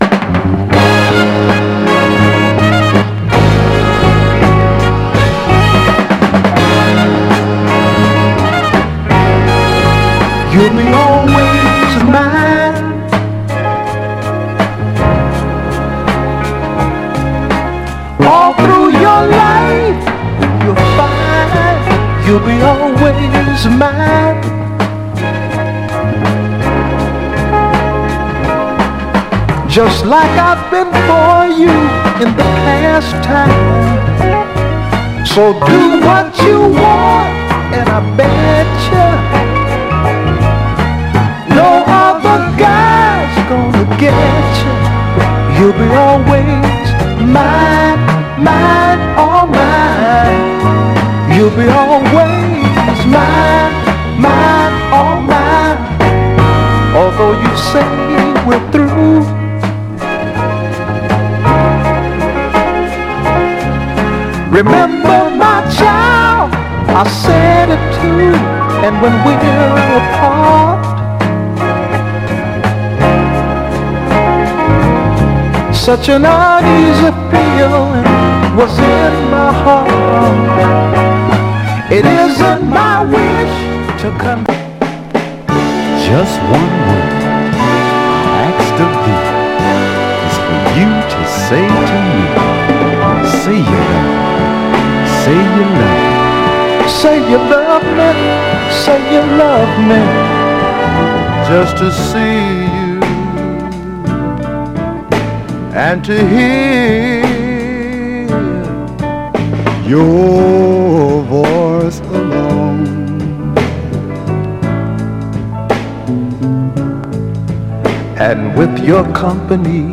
蕩ける極上バラード
爆発力のあるホーン隊も活きたクロスオーヴァーなメロウ・ソウルで人気です。
※試聴音源は実際にお送りする商品から録音したものです※